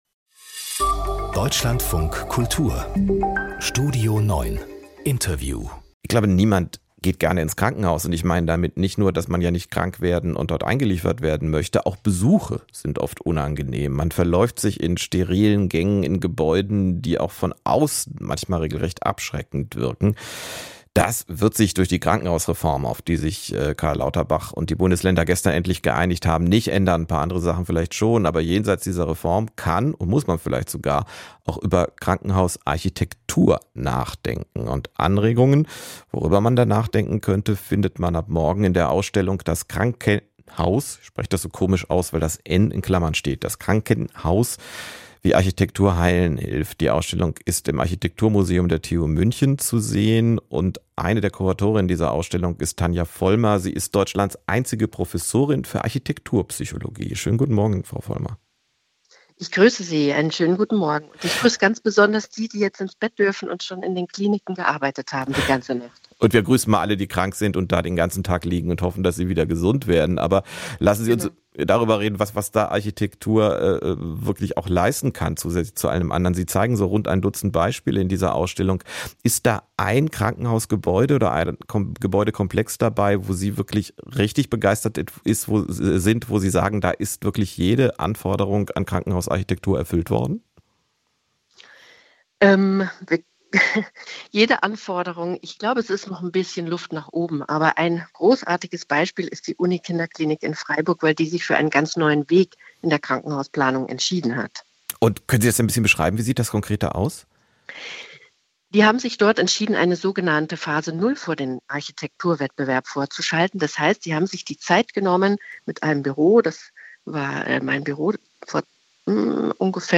Das Interview im Deutschlandfunk Kultur greift kulturelle und politische Trends ebenso auf wie... Mehr anzeigen